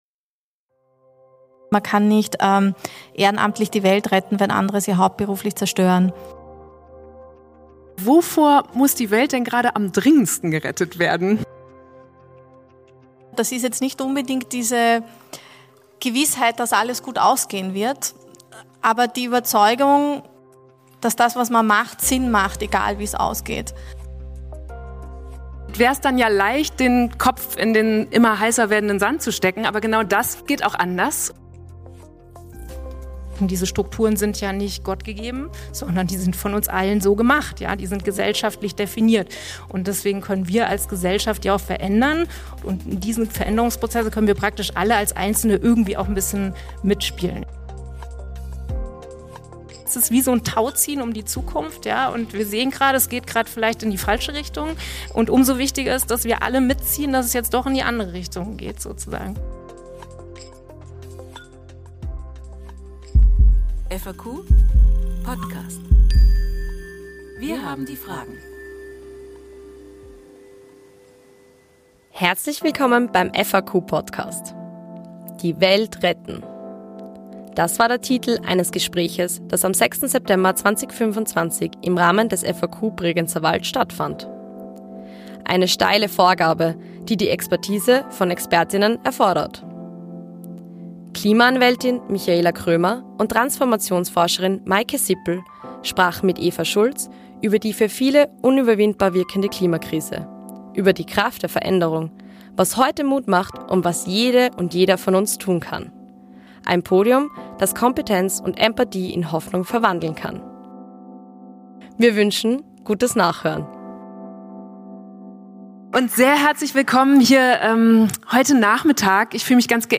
Im Gespräch
aufgezeichnet beim FAQ Bregenzerwald am 6. September 2025 in der Zimmerei und Tischlerei Kaufmann in Reuthe